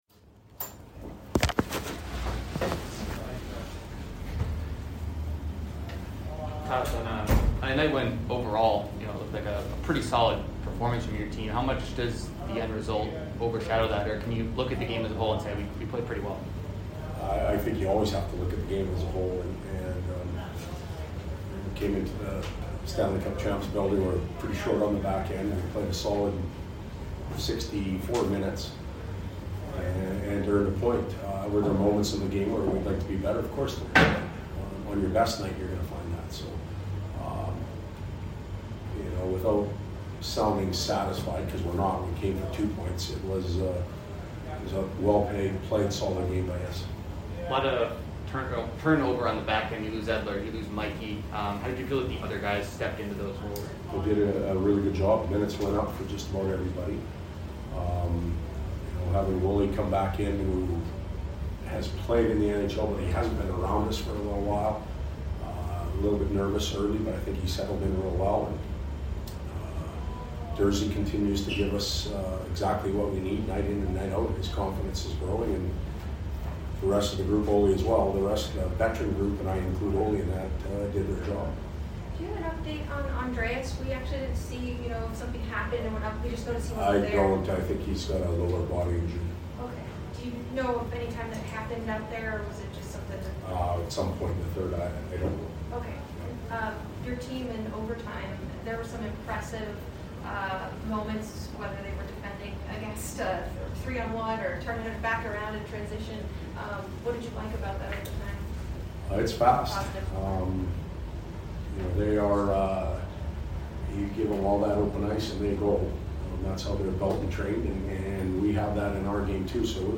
Head Coach Todd McLellan Post Game 12 - 14 - 21